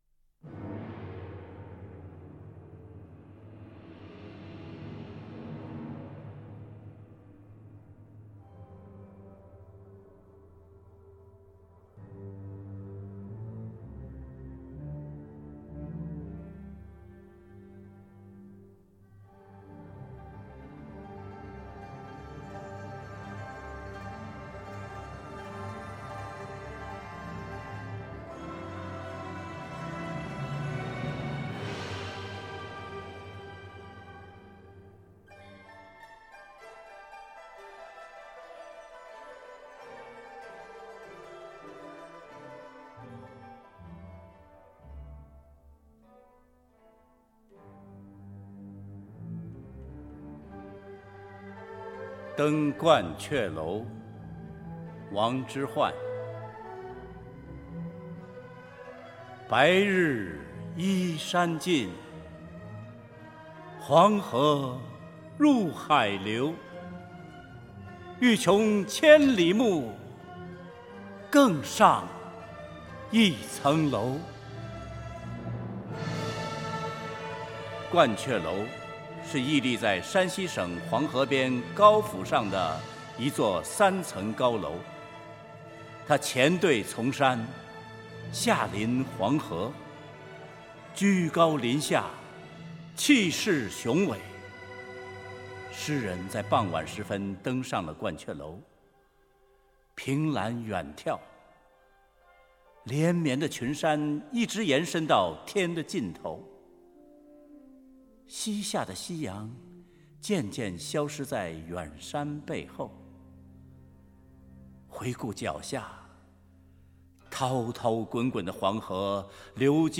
融文学 朗诵 音乐于一炉的艺术精品
配乐后的唐诗朗诵，字字珠玑，实在篇篇天籁。
他的声音的确很迷人！